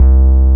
46BASS01  -R.wav